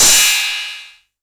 808CY_2_TapeSat.wav